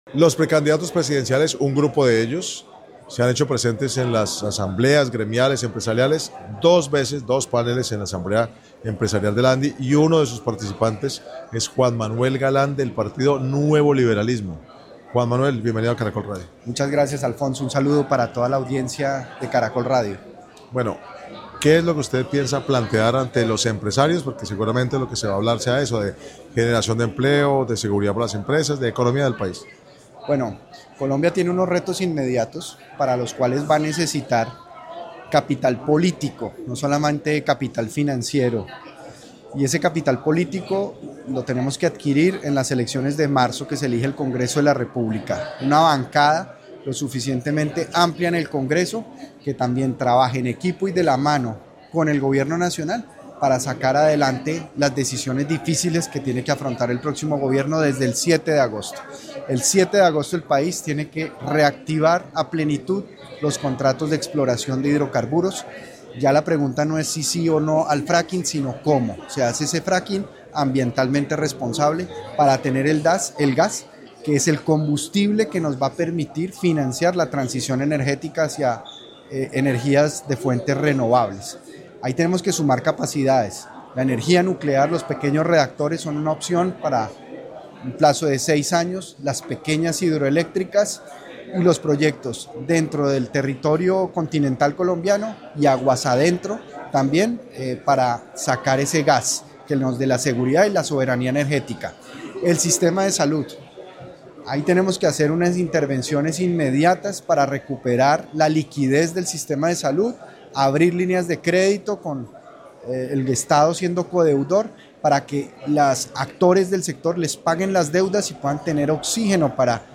En el marco del Congreso de la ANDI, que se realizó en la ciudad de Cartagena, Caracol Radio habló con el precandidato presidencial del Nuevo Liberalismo, Juan Manuel Galán, quien hizo un análisis sobre lo que será la contienda electoral del próximo año.